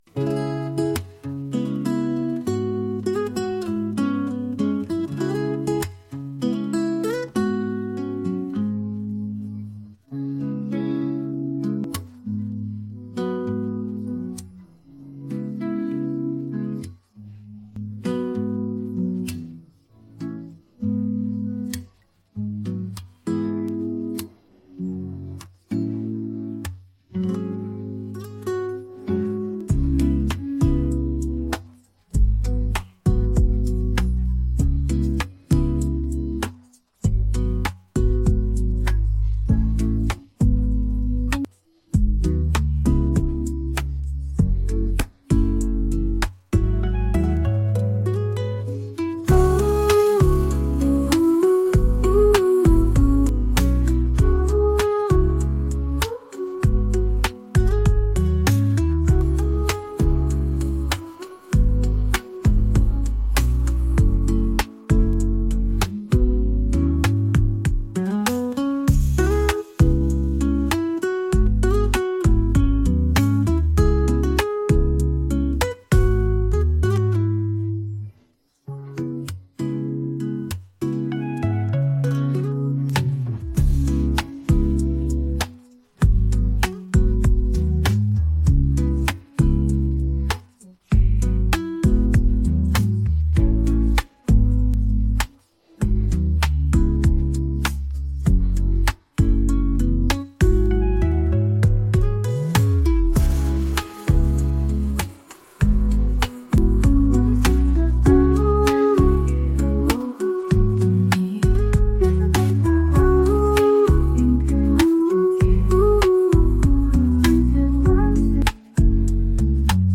MP3歌曲背景音樂分離
仔細聽了一下，AI 處理分離人聲的效果比預想的好很多，包含過場音樂的「嗚阿喔」都有正確被分離，聽起來不像傳統的軟體會有很多雜音，無論是人聲與音樂的音軌聽起來都很乾淨，如果需要作為後製會是很好的素材來源。
be17ecf3-天青色等煙雨-而我在等你_樂器.mp3